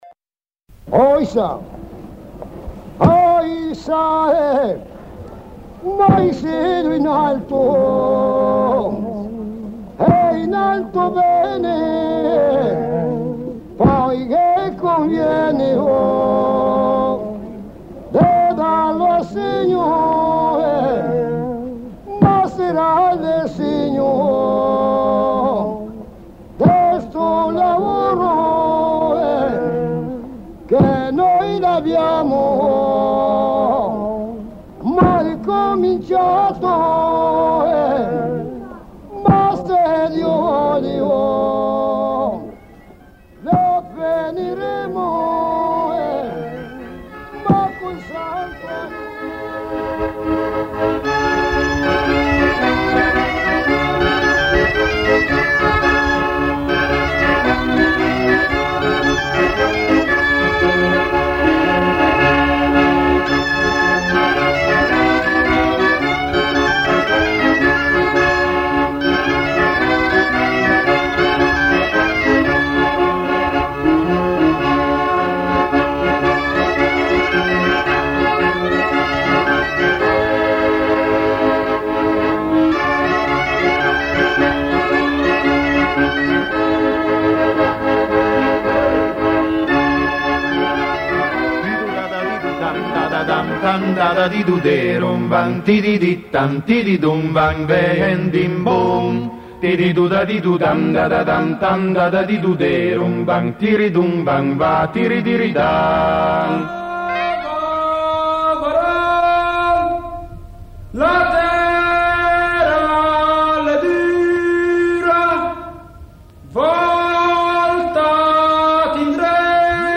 Settimanale radiodiffuso di musica, musica acustica, musica etnica, musica tradizionale popolare, di cultura popolare, dai...